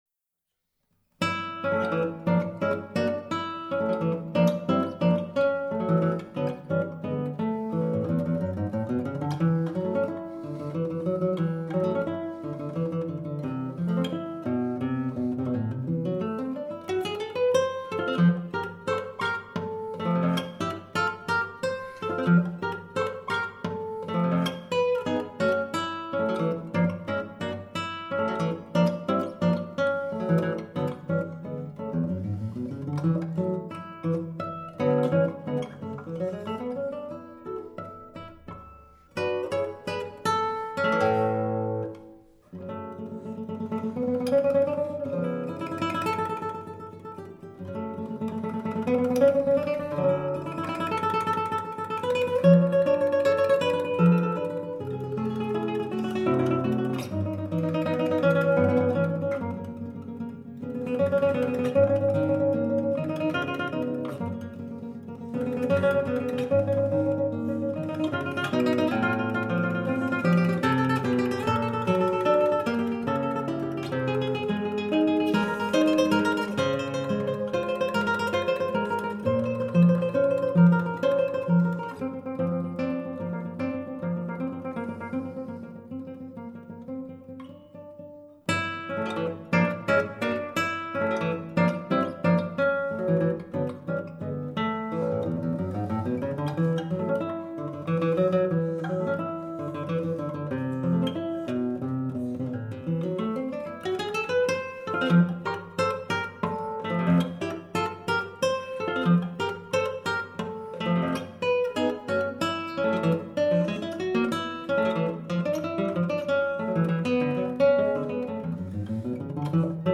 guitar
a natural melodic flair, and the bitter-sweet harmony